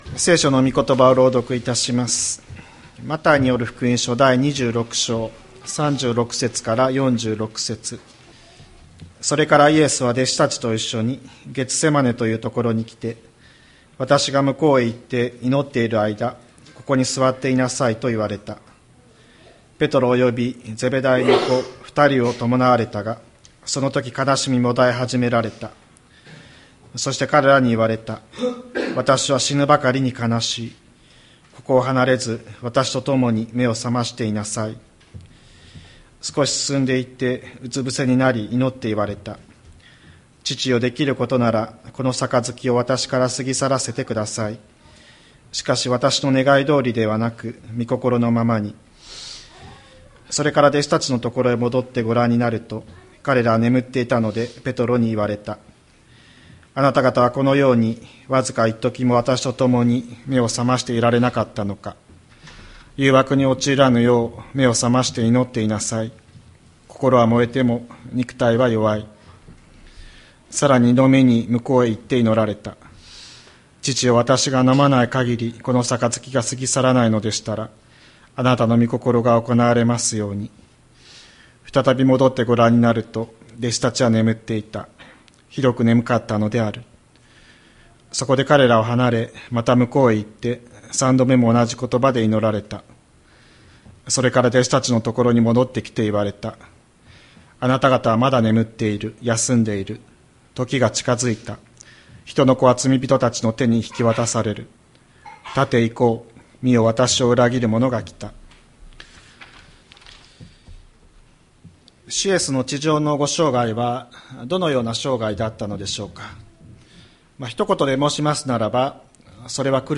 千里山教会 2025年03月23日の礼拝メッセージ。